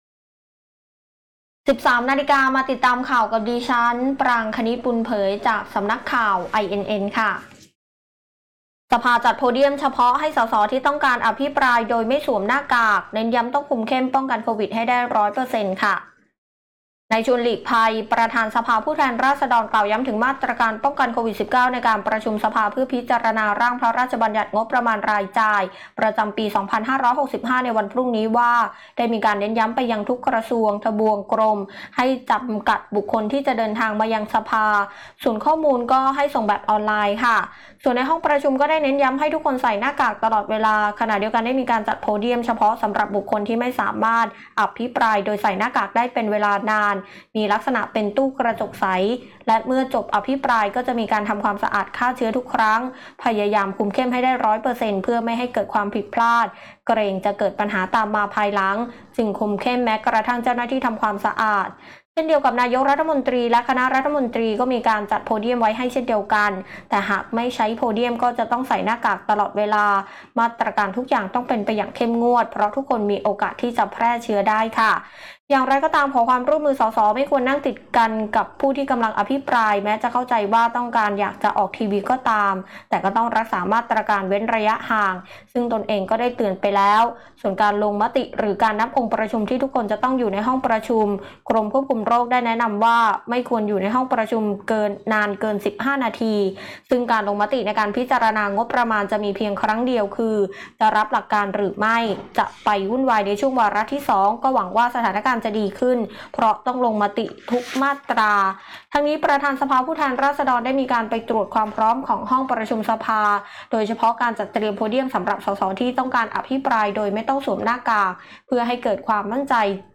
คลิปข่าวต้นชั่วโมง
ข่าวต้นชั่วโมง 13.00 น.